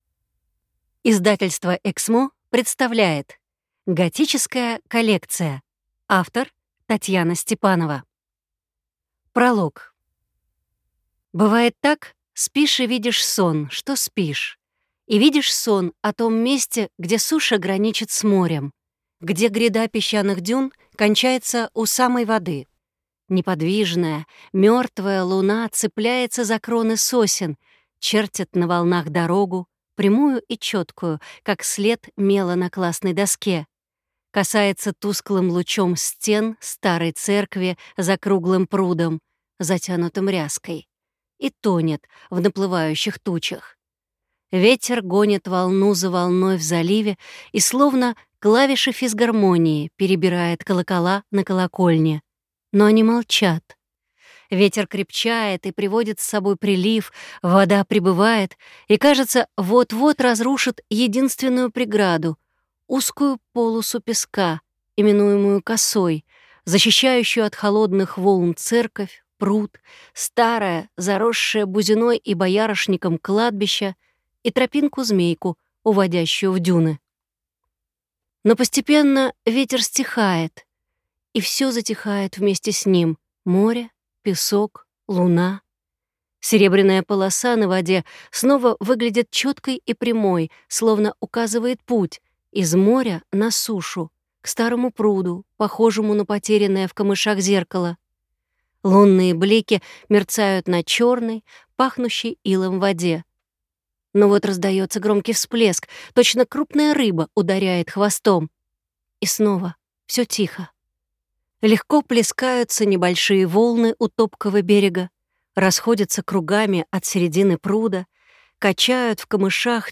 Аудиокнига Готическая коллекция | Библиотека аудиокниг